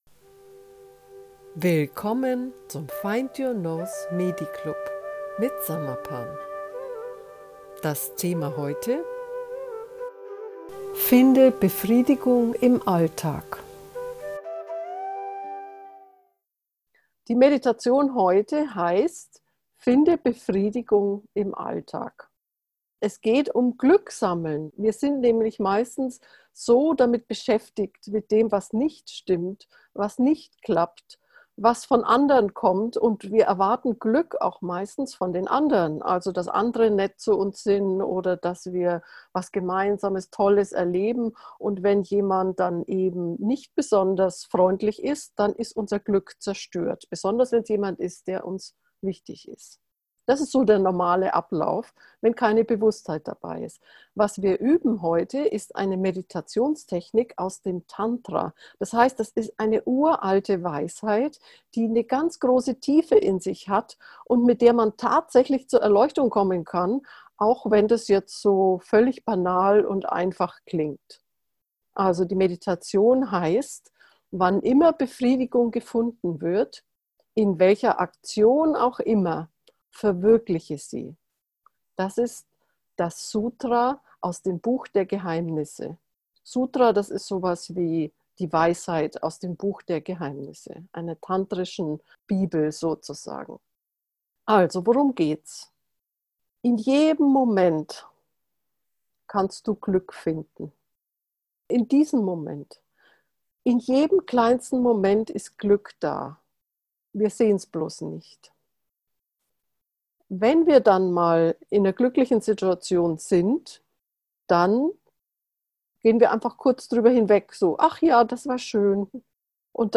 Die Meditation beruht auf einer überlieferten, uralten Weisheit. Die Meditationsphase ist 10 Minuten lang.
finde-befriedigung-meditation